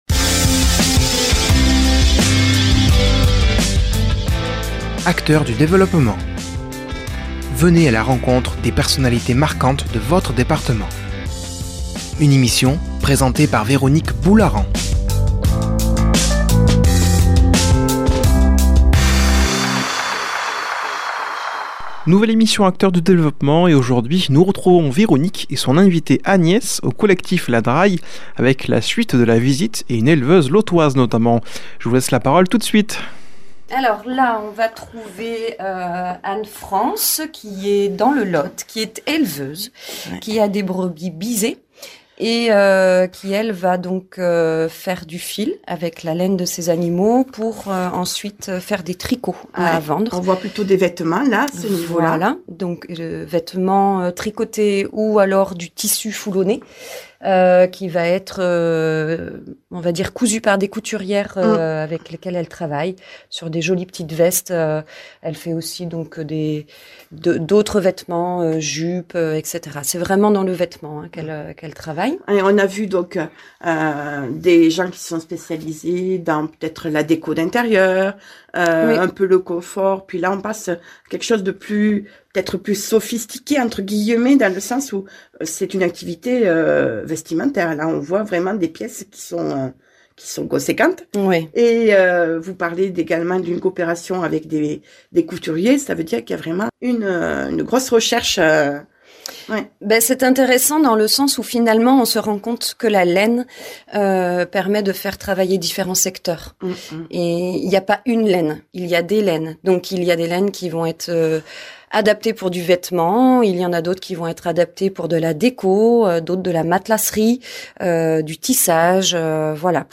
[ Rediffusion ] Aujourd'hui dans Acteur du Développement